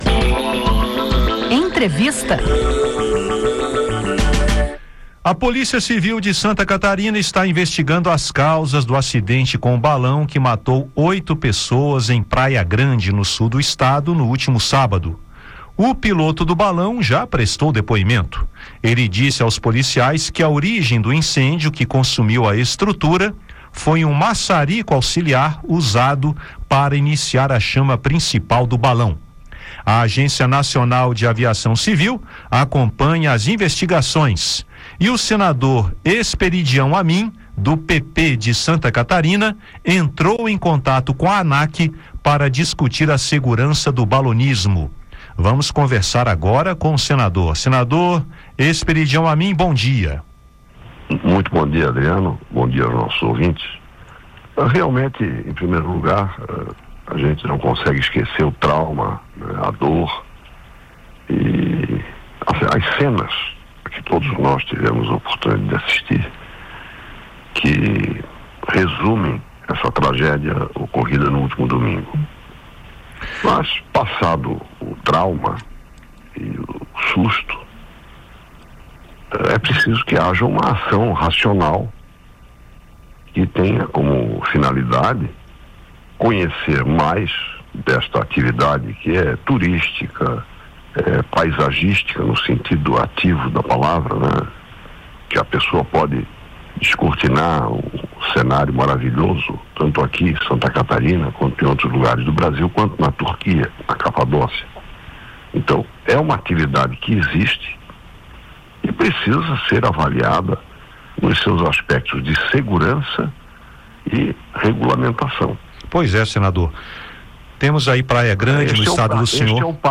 O senador Esperidião Amin (PP-SC) entrou em contato com a agência para discutir a segurança do balonismo e avaliar se novas leis são necessárias para aprimorar as regras do setor. Em entrevista, ele comenta as medidas estudadas pela Anac depois da tragédia e a possibilidade de realização de audiências públicas no Senado sobre o assunto.